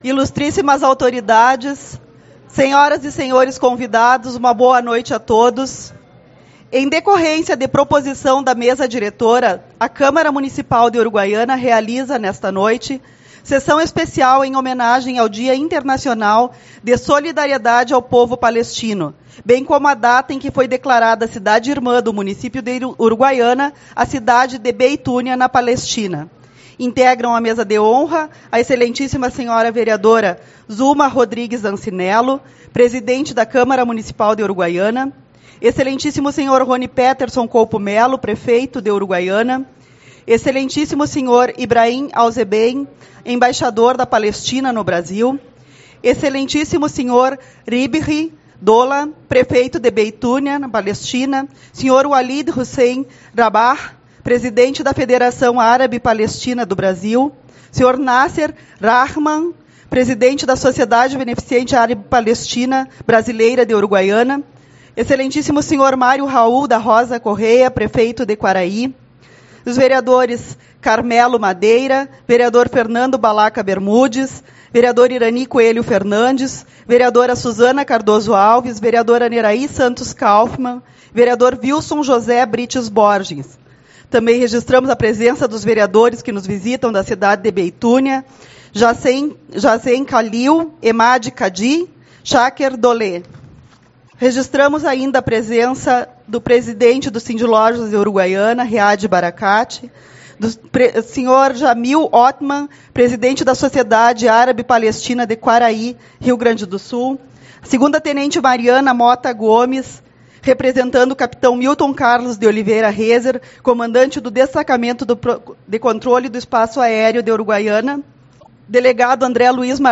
29/11 - Sessão Especial-Dia Internacional de Solidariedade ao Povo Palestino